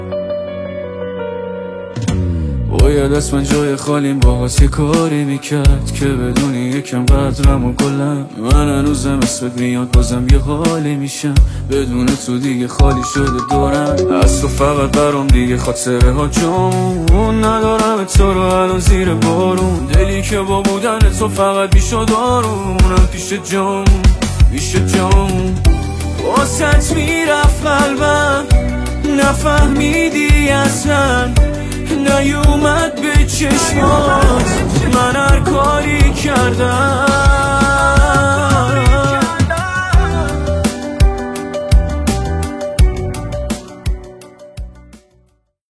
گوش کنید موزیک عاشقانه